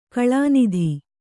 ♪ kaḷānidhi